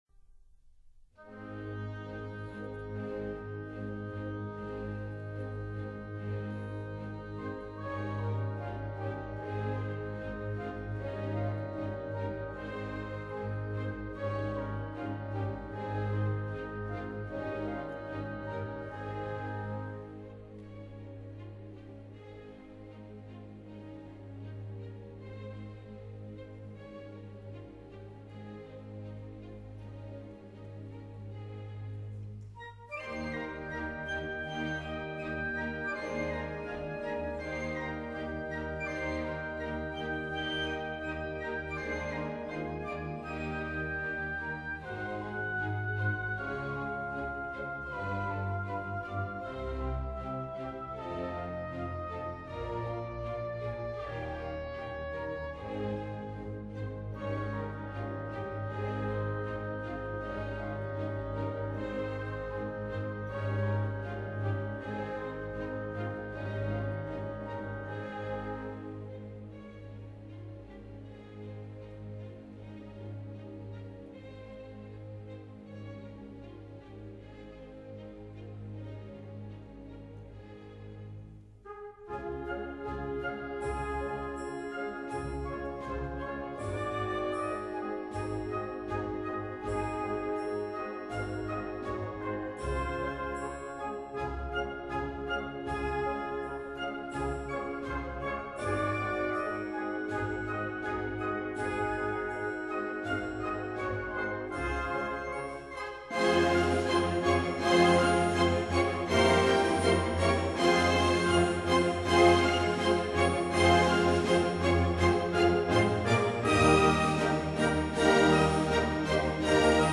Allegro un peu loure
快速的罗瑞舞曲<03:18>